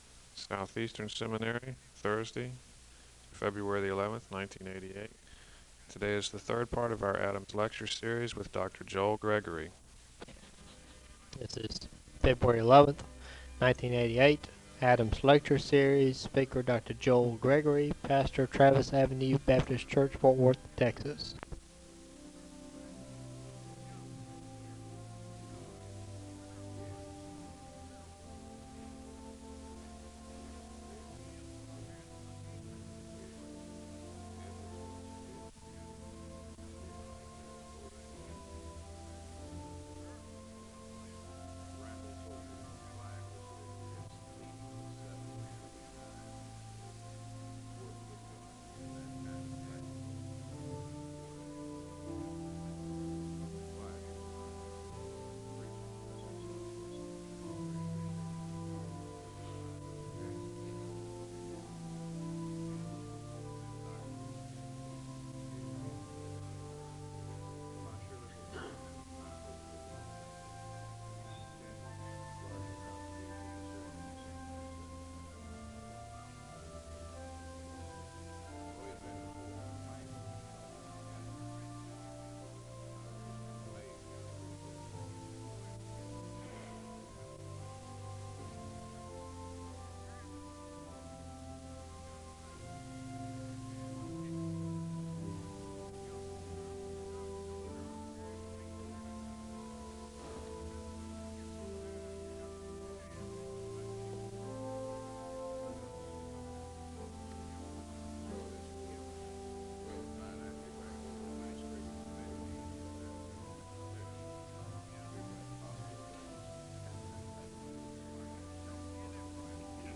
The service begins with organ music (0:00-3:57). There is a moment of prayer (3:58-5:32).
The service concludes with a benediction (39:29-39:51).